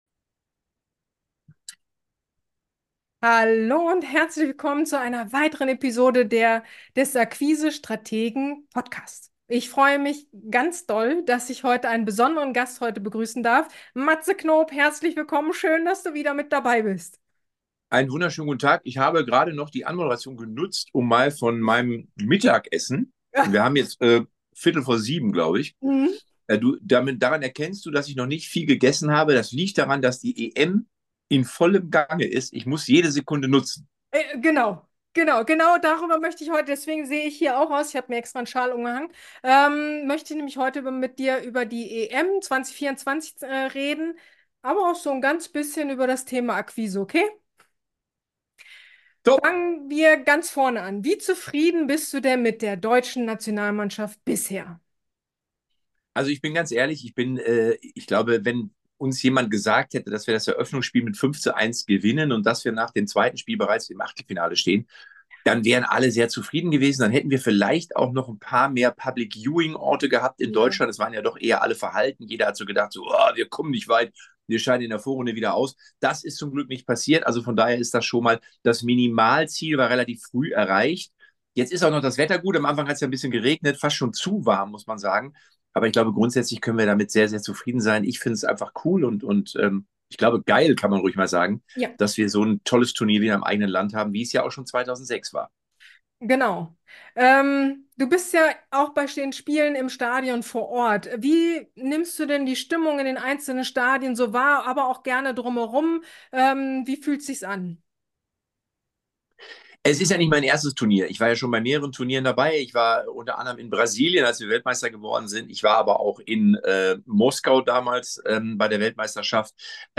Interview mit Fußball-Experte & Comedian Matze Knop - Wie ist die Stimmung im Land während der EM 2024 #30 ~ akQuise strategen - Podcast